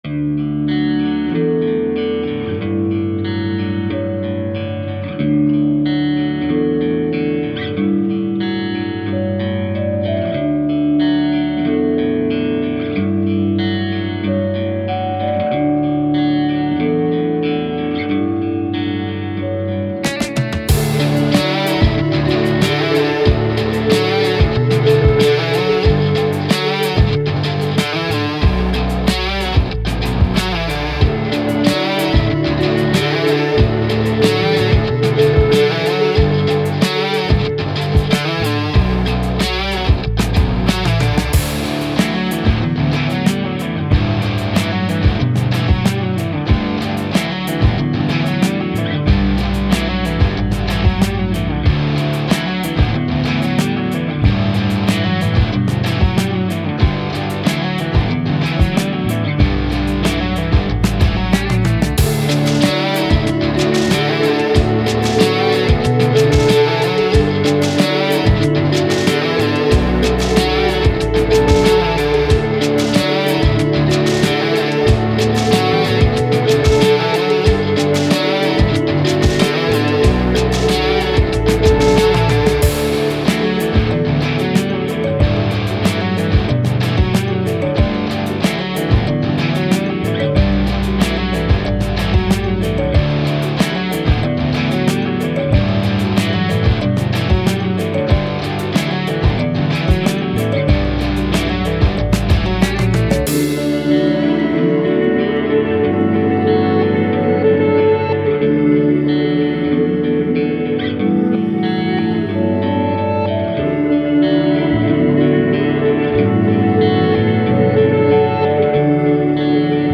Indie, Rock, Guitars, Thoughtful, Action